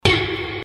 SFX嘿音效下载
SFX音效